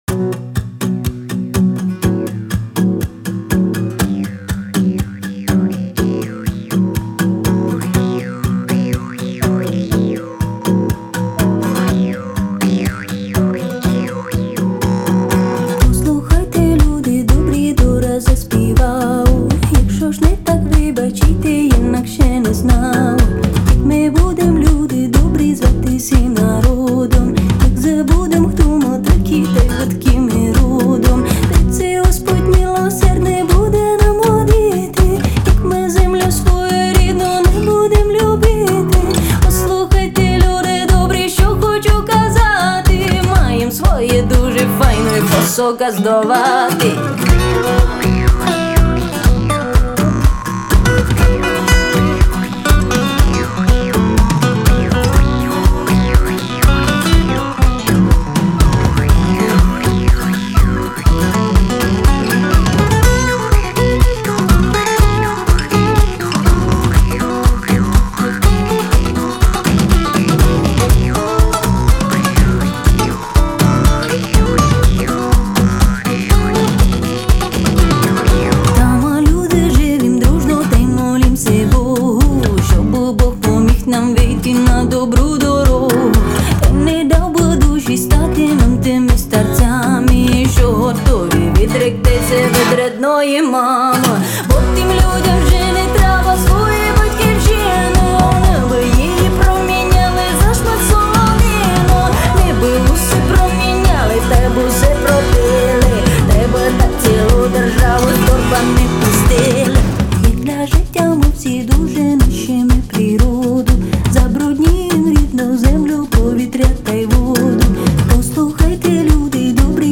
• Жанр:Поп, Народна